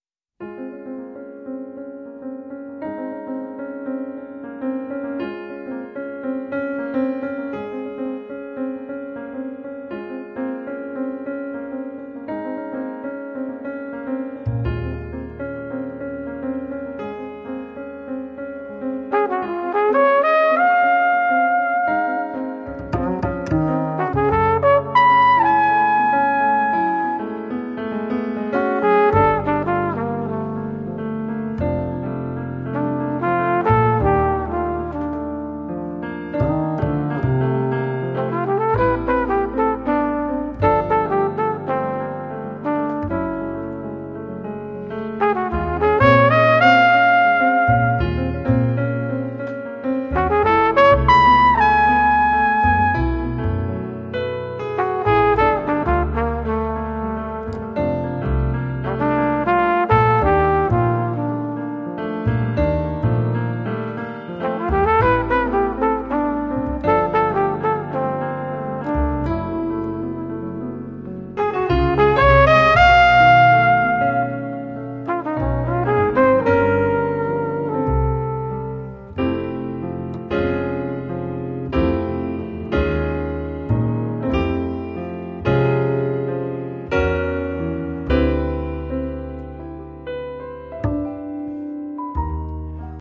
piano
tromba, flicorno
basso